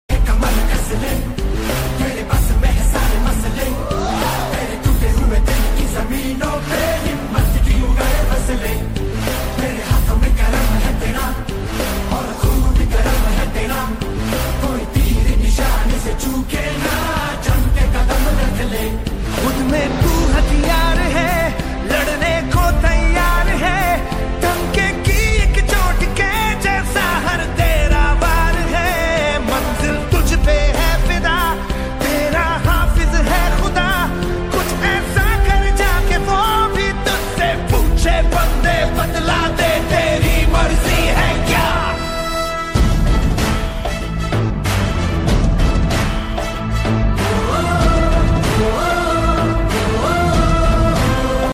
Fight sound effects free download